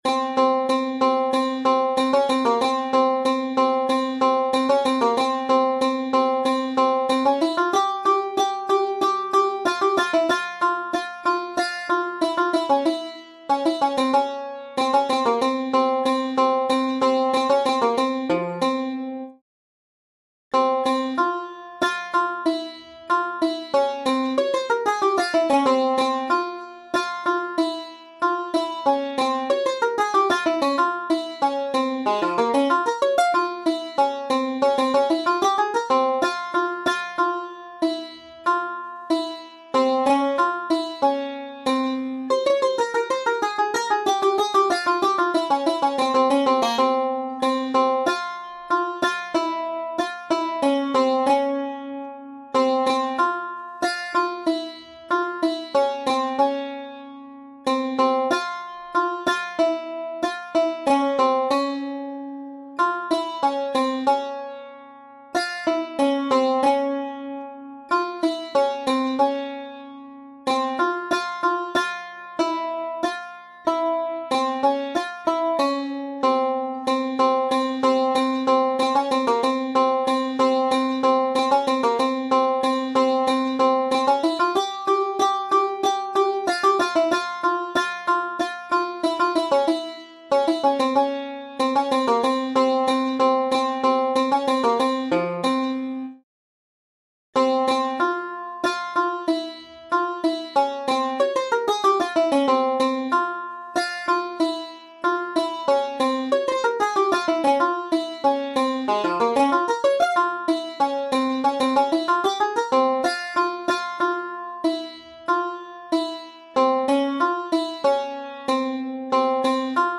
تنظیم :